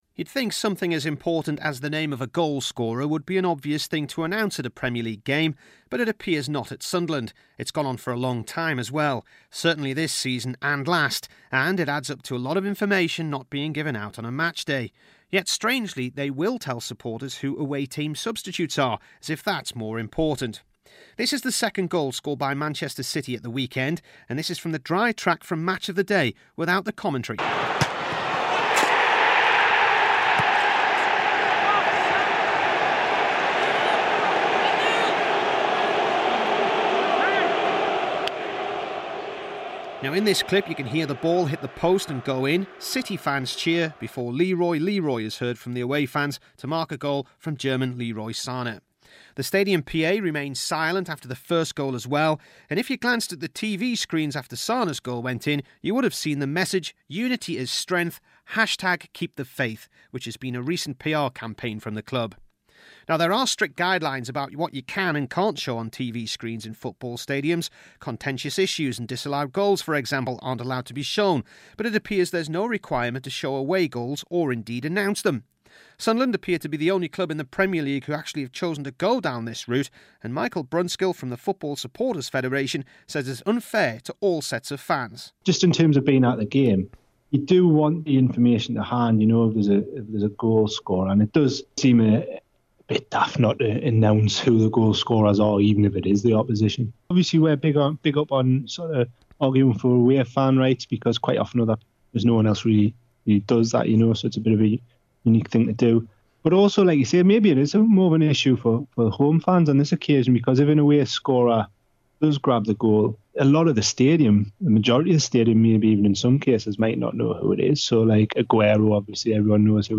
BBC NEWCASTLE REPORT ON SUNDERLAND AFC AND WHY THEY DON'T TELL YOU WHO THE AWAY GOALSCORERS ARE.